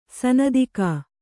♪ sanādikā